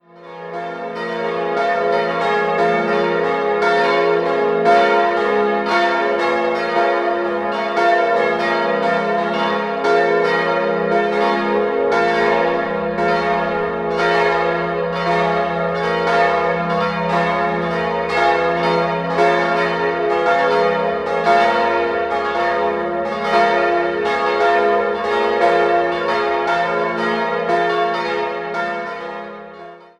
Idealquartett f'-as'-b'-des'' Die Glocken wurden Anfang der 1970er-Jahre, vermutlich von Rudolf Perner, gegossen.